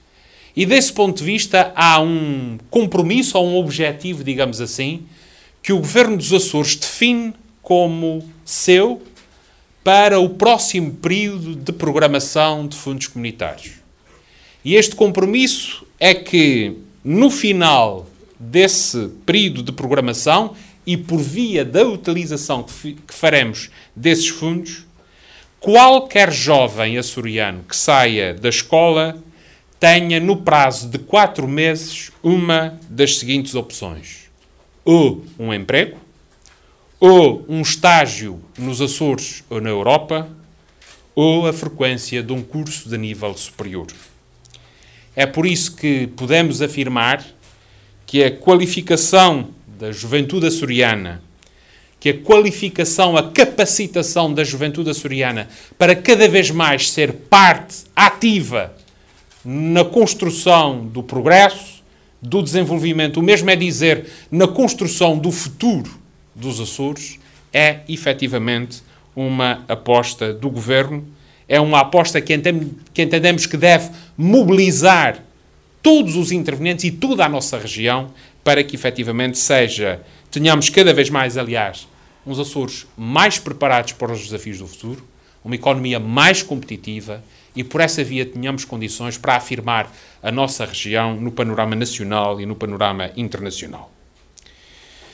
“Este é um objetivo que o Governo dos Açores define como seu para o próximo período de programação de fundos comunitários”, assegurou Vasco Cordeiro, que falava na inauguração do Centro de Formação do Belo Jardim, localizado na Praia da Vitória.